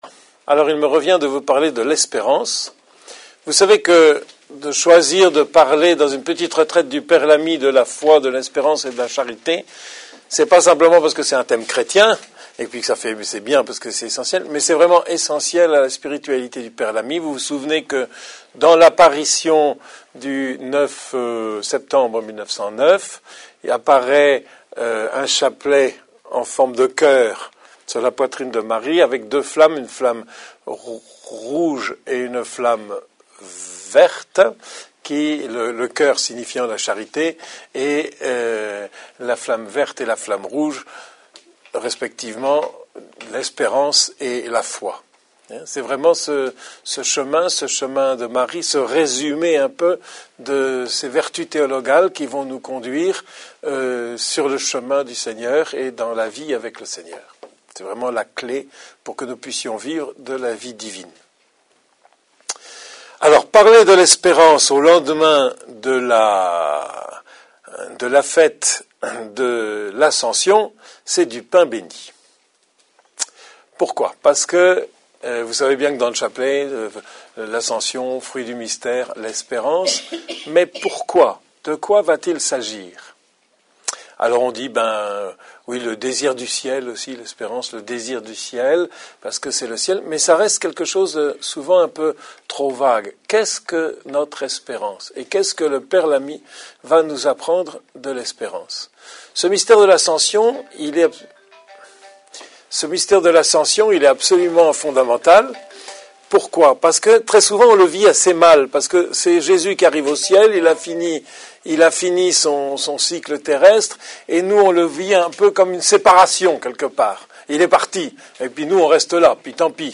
Conférences audio de la retraite à Notre-Dame des Bois